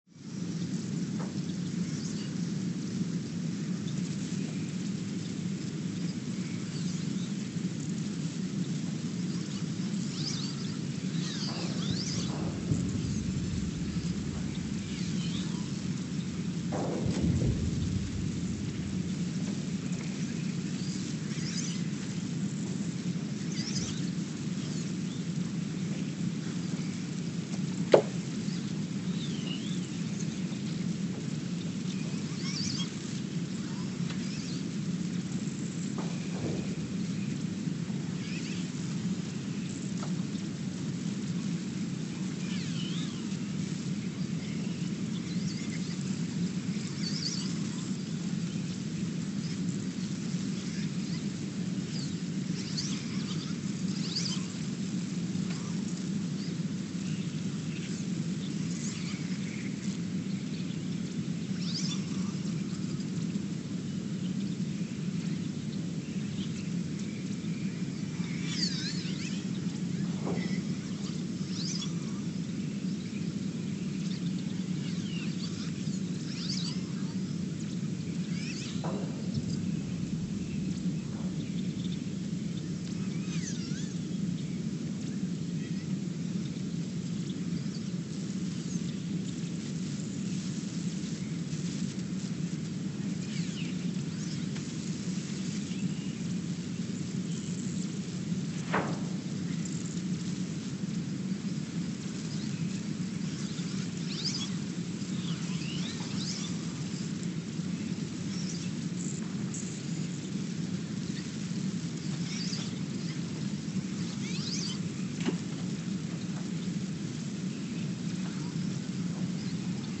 Ulaanbaatar, Mongolia (seismic) archived on February 8, 2024
No events.
Sensor : STS-1V/VBB
Speedup : ×900 (transposed up about 10 octaves)
Loop duration (audio) : 03:12 (stereo)
SoX post-processing : highpass -2 90 highpass -2 90